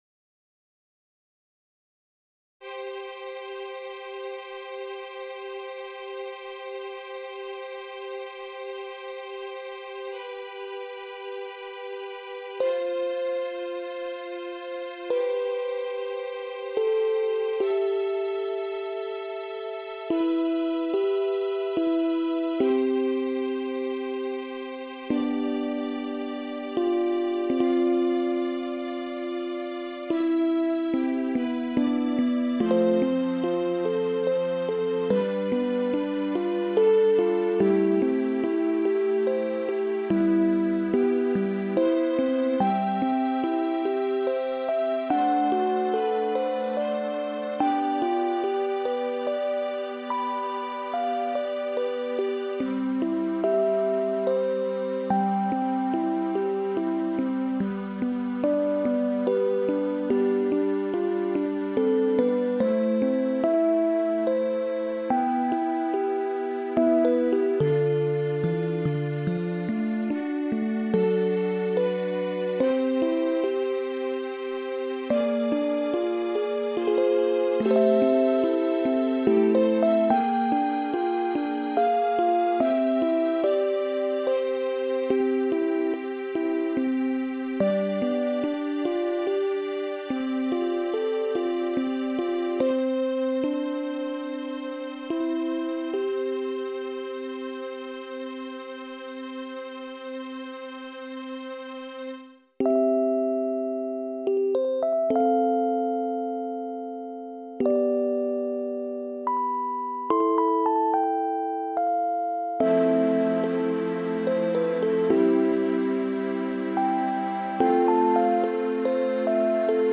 Walz ( Title Unknown )